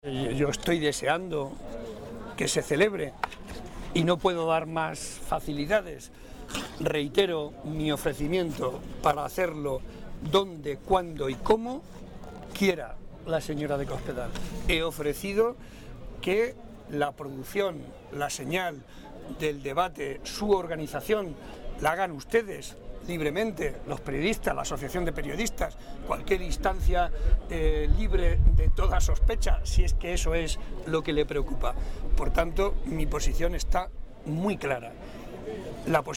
El presidente de Castilla-La Mancha y candidato a la reelección, José María Barreda, aseguró hoy, en declaraciones a los medios de comunicación en Villanueva de la Torre (Guadalajara), que está deseando mantener un debate con la candidata del PP a la Presidencia, María Dolores de Cospedal, para que, de cara al próximo 22 de mayo, los ciudadanos puedan escuchar lo que ofrece uno y otra para la Región.
Cortes de audio de la rueda de prensa